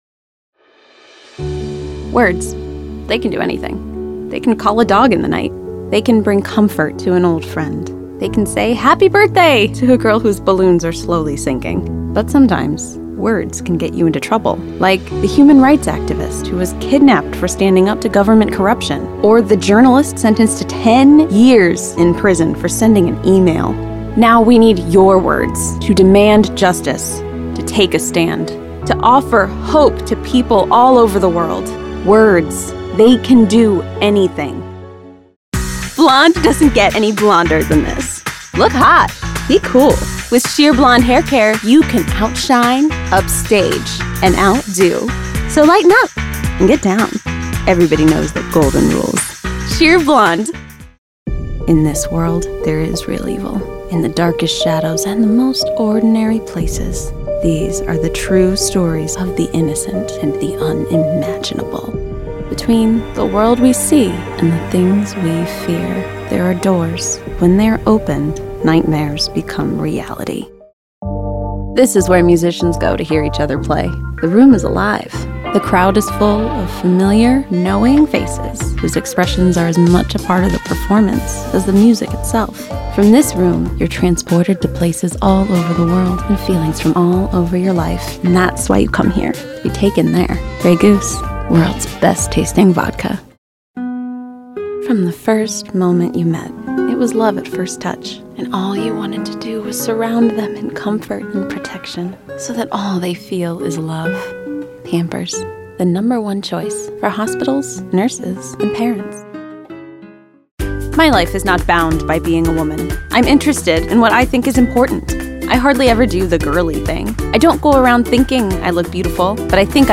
Voiceover Reel